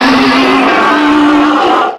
Cri de Brutapode dans Pokémon X et Y.